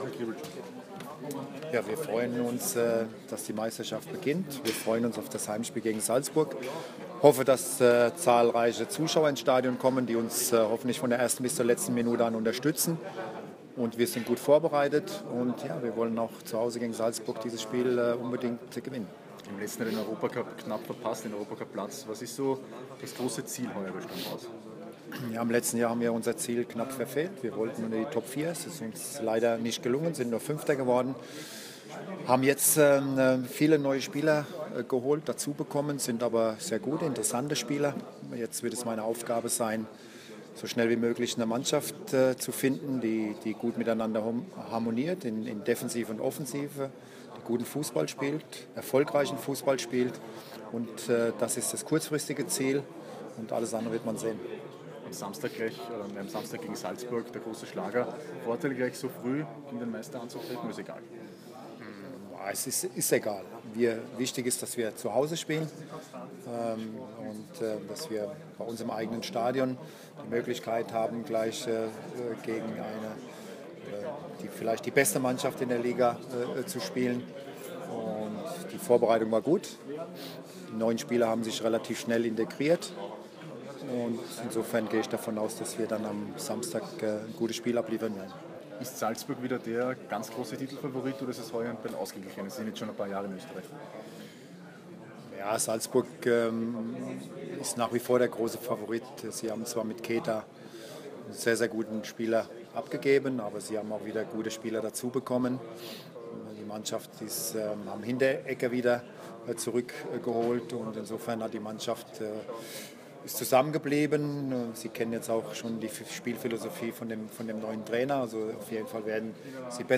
Franco Foda im Interview